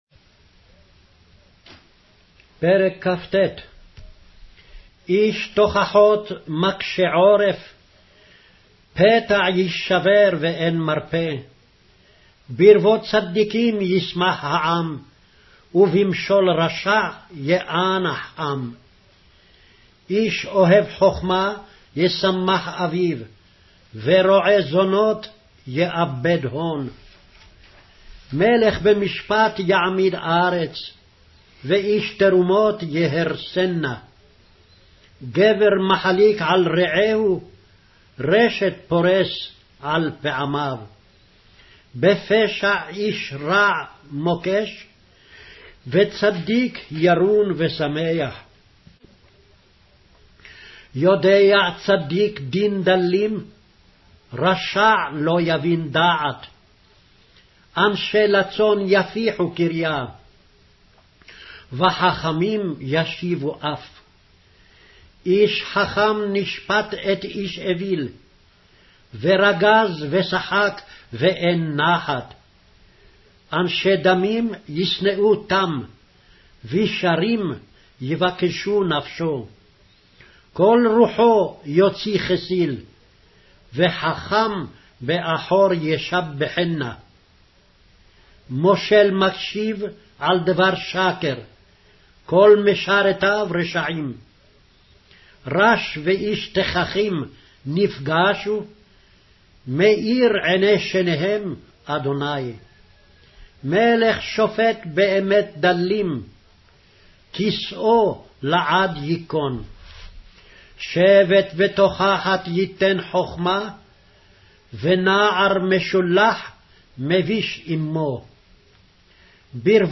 Hebrew Audio Bible - Proverbs 4 in Orv bible version